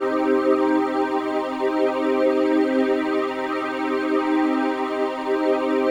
PHASEPAD01-LR.wav